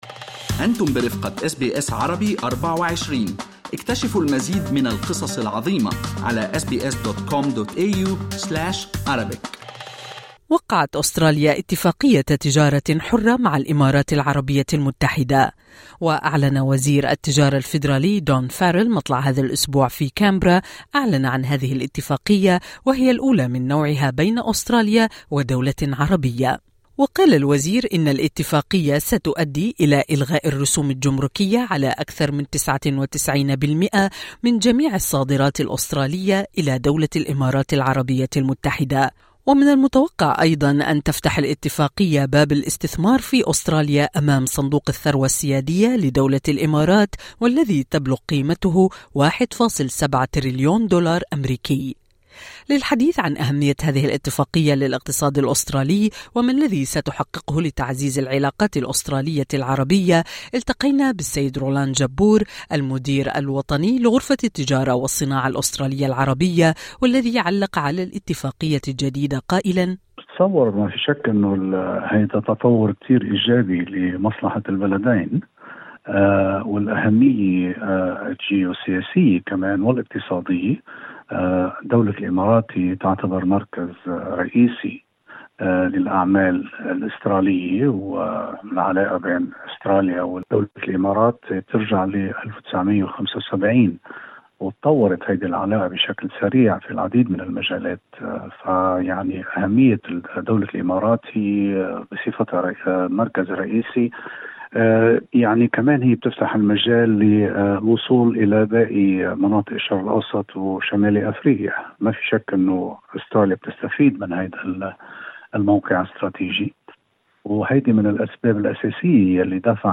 اللقاء الكامل